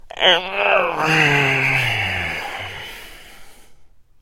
Звуки потягивания
Утренний звук мужской протяжки